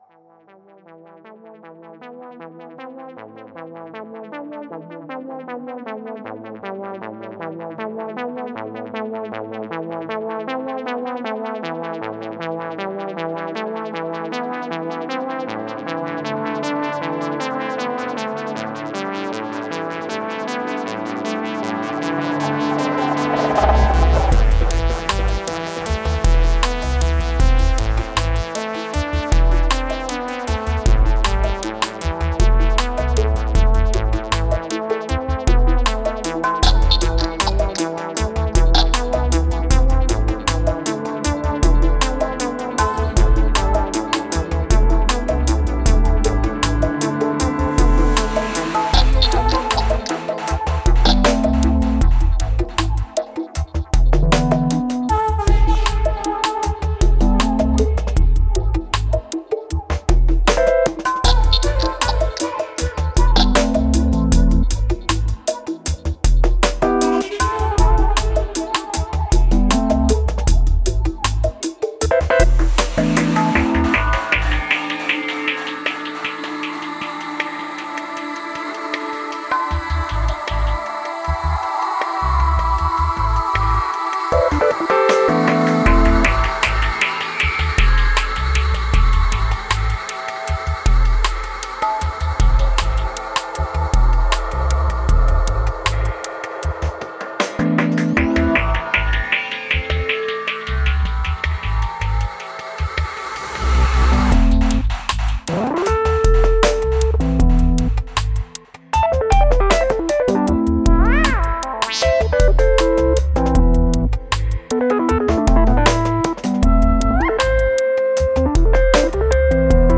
Genre: Psychill, Downtempo.